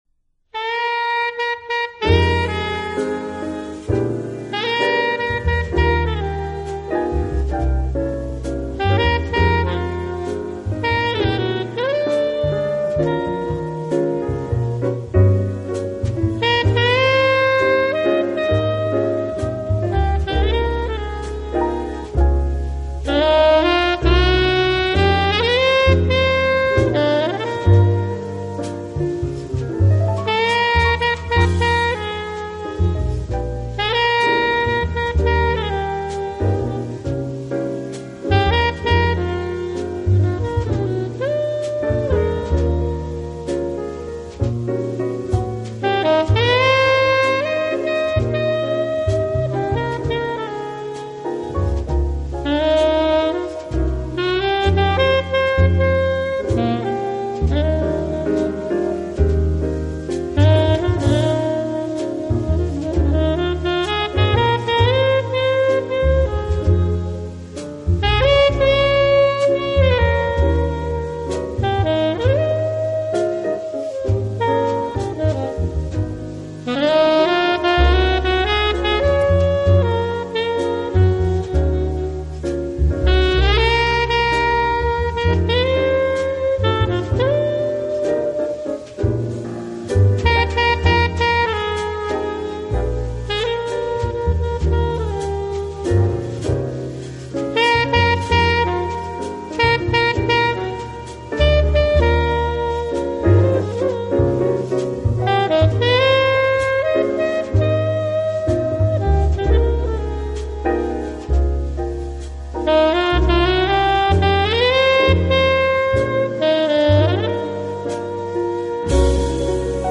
流派：Jazz